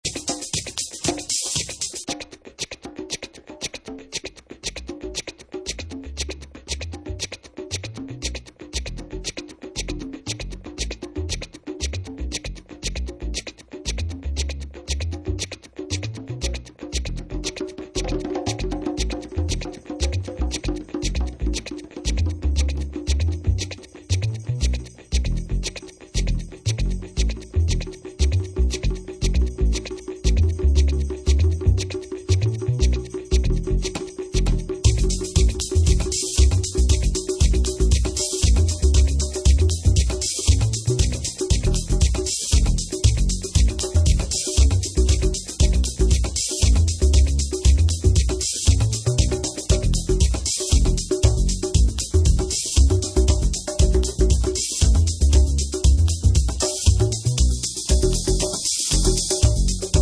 Moody and deep vocal house track.. one sided press.